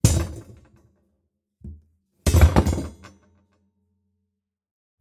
На этой странице собраны разнообразные звуки железной палки: от резких звонких ударов до протяжных вибраций.
Роняем тяжелую железную балку